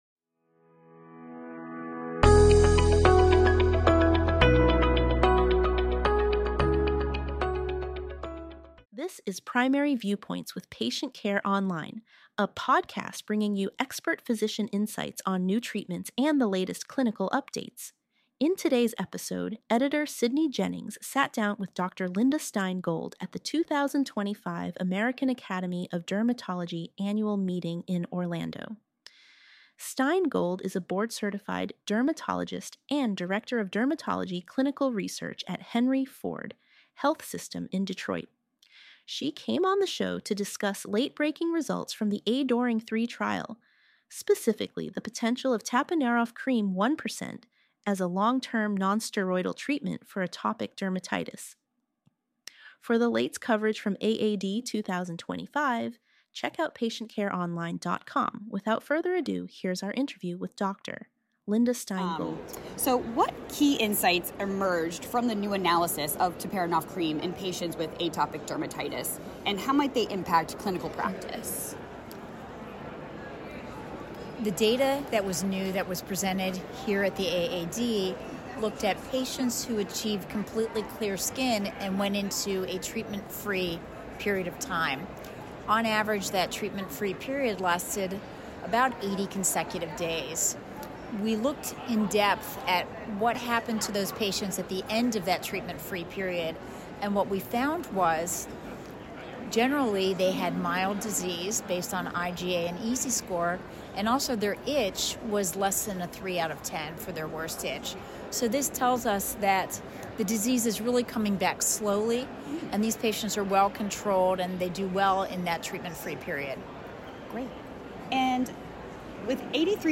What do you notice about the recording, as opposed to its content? Interview recorded at the 2025 American Academy of Dermatology conference in Orlando, Florida.